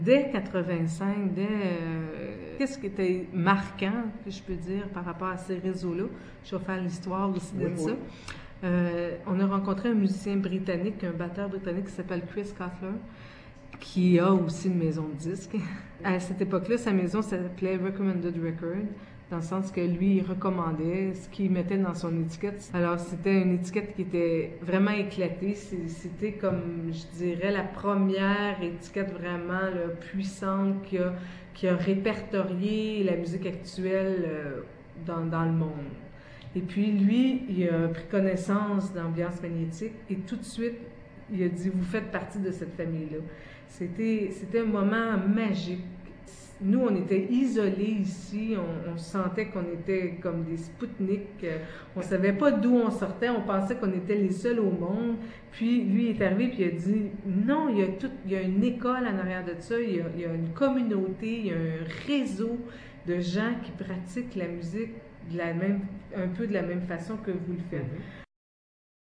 Résumé de l'entrevue :